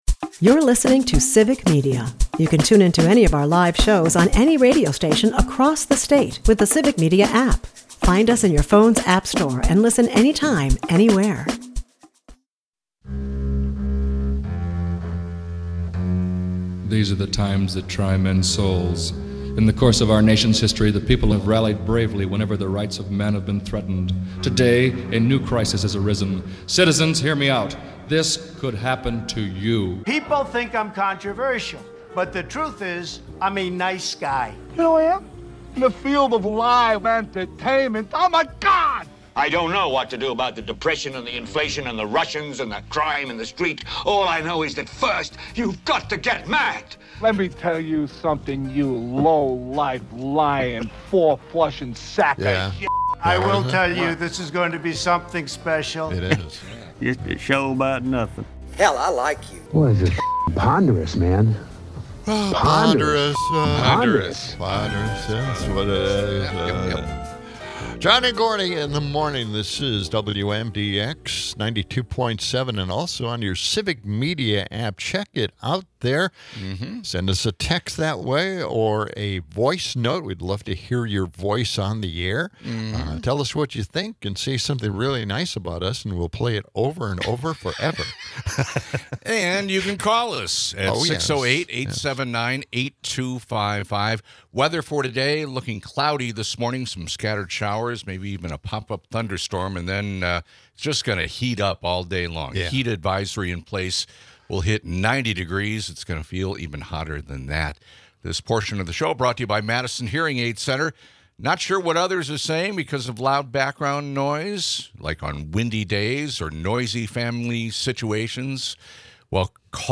They also navigate Trump's bizarre drug pricing strategy, which aims to raise European drug costs to lower U.S. prices. Callers reminisce about legendary local haunts while critiquing media control.